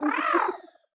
Cri de Piétacé dans Pokémon Écarlate et Violet.